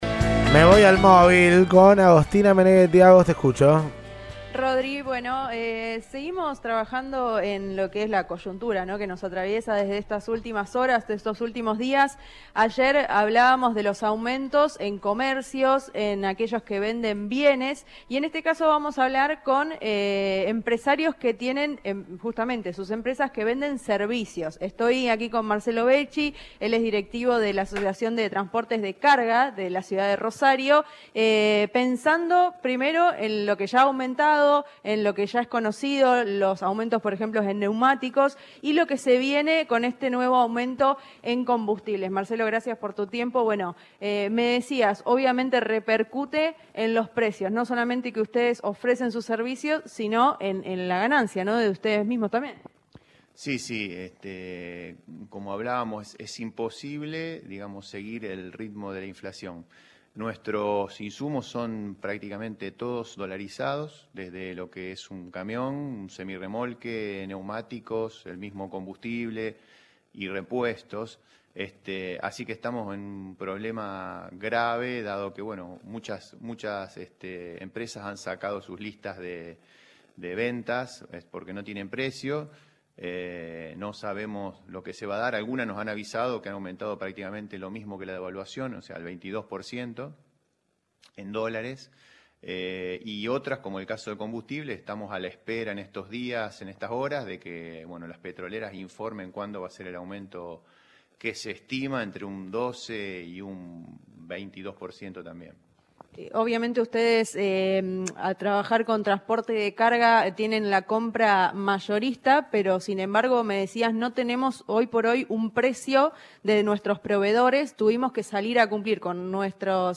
En diálogo con el móvil de Cadena 3 Rosario